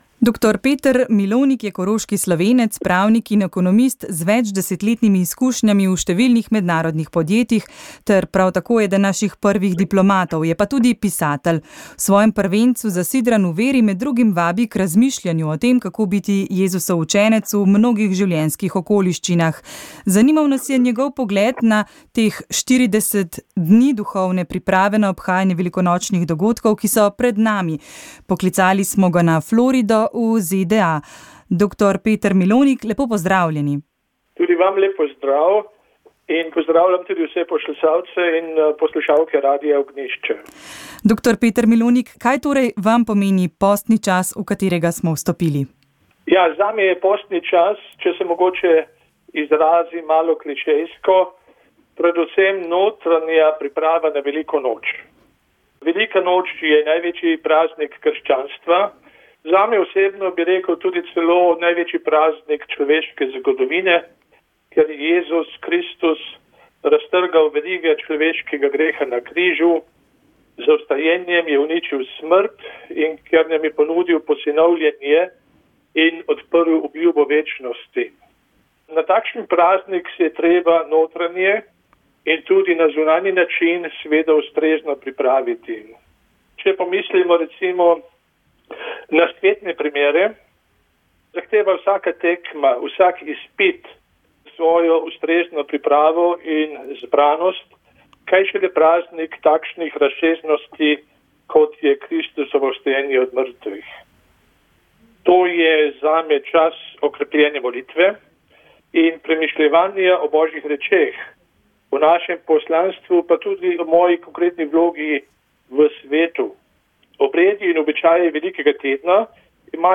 Ne gre za vprašanje: evtanazija – DA ali NE, ampak za vprašanje - paliativa DA ali NE, je na predstavitvi izjave Komisije pravičnost in mir dejal njen predsednik nadškof Anton Stres. Opozoril je na zlorabe, ki se dogajajo v državah, kjer je uzakonjena, in dodal, da pri evtanaziji ni vključena samo oseba, ki jo zahteva, ampak celotna družba.